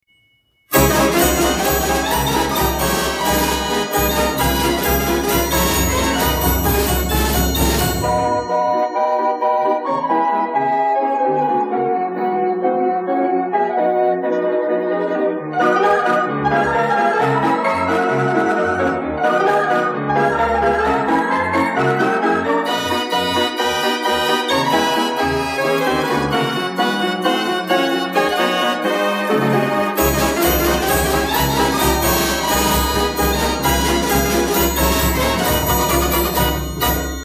Wurlitzer Model C Orchestrion